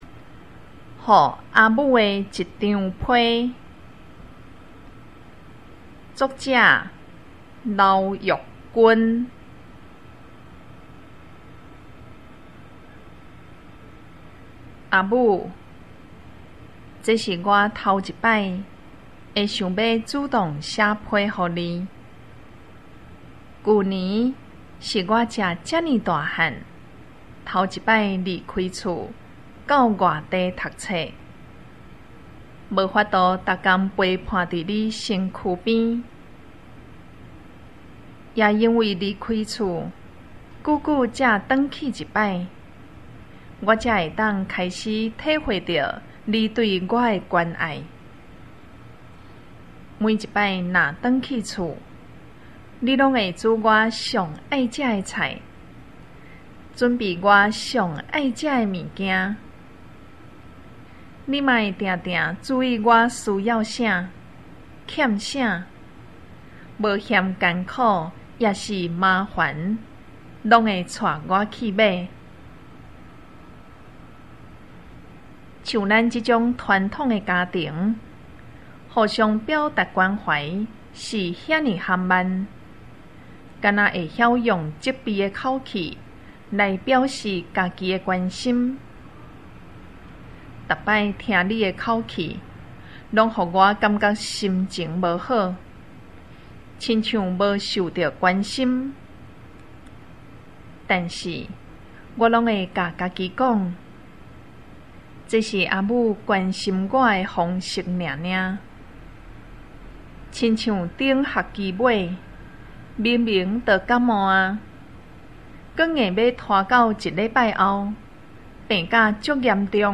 校內多語文競賽---公告閩南語朗讀---篇目2篇 & 朗誦示範Demo帶